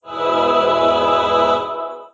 choir
Harmonic vocal-like.